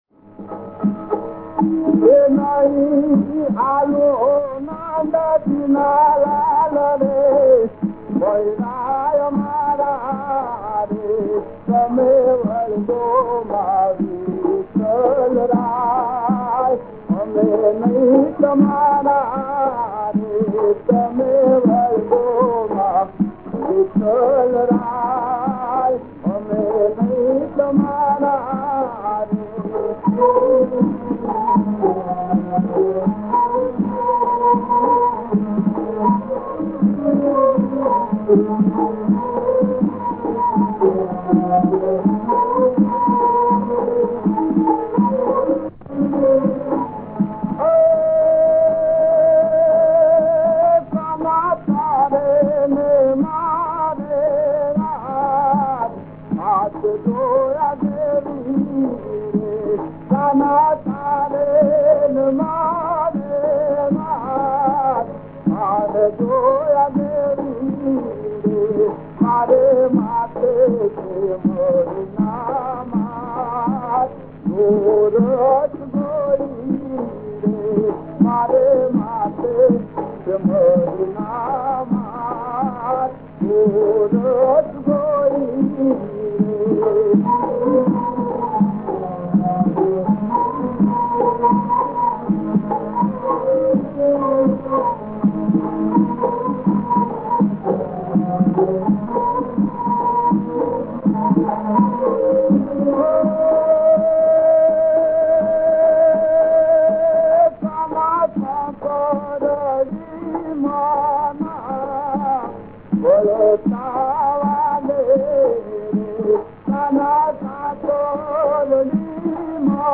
નહી આપું હો નંદજીનાં લાલ રે… (લોકગીત) | આનંદ આશ્રમ